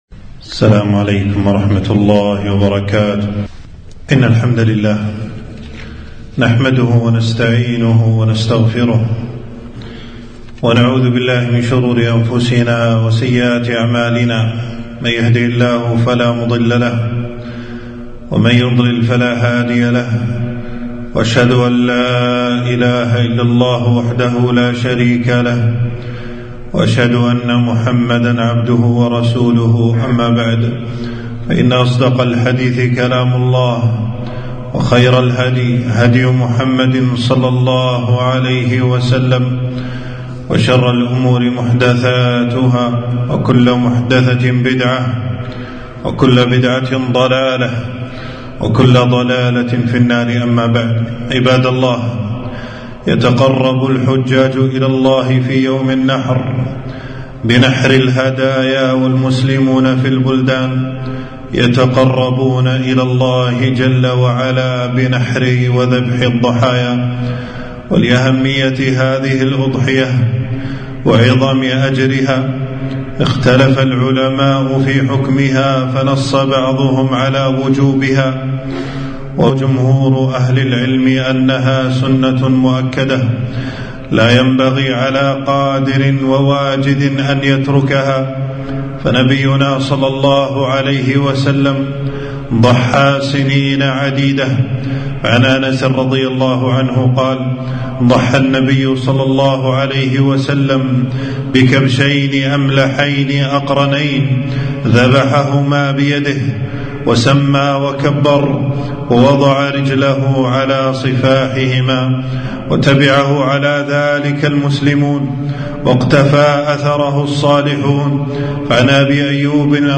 خطبة - أحكام الأضاحي والعيد